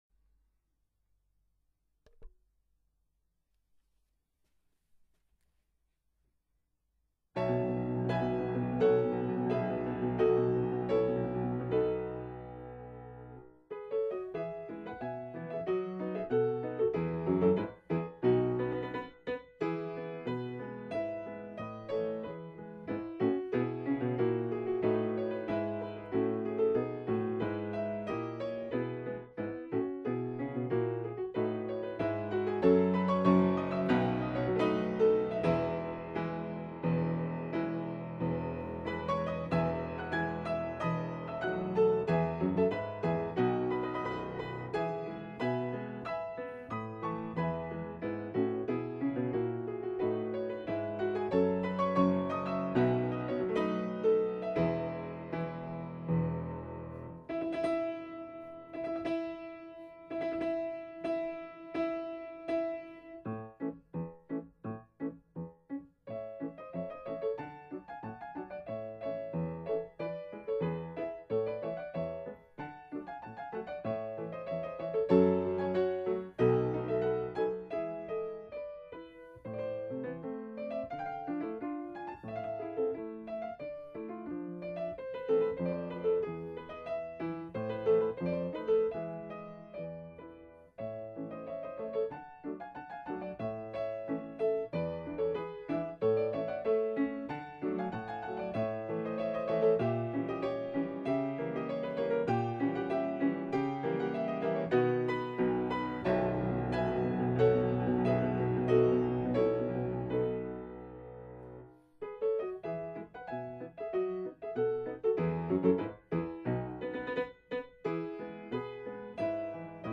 Doch schon 1880 wurde die Deutsche Bank selbst Thema einer musikalischen Komposition - in Form eines Festmarsches: vier Seiten Noten für Klavier, in E-Dur, Tempo di marcia.
an der Hochschule für Musik, Frankfurt am Main, eingespielt
Das zentrale Motiv des Liedes setzt nach der Fermate am Ende des Generalauftaktes ein und wird im Stück mehrfach wiederholt.